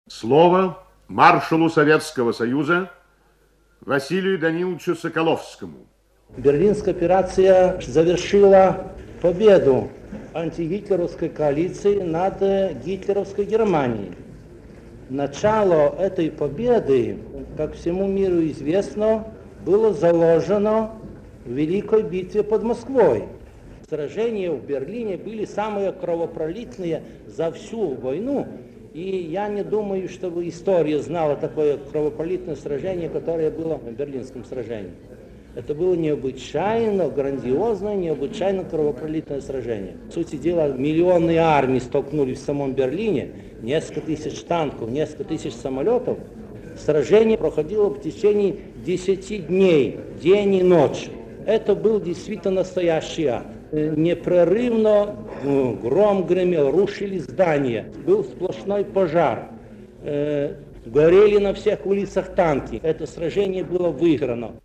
Маршал Советского Союза Василий Соколовский рассказывает о значении битвы за Берлин (Архивная запись).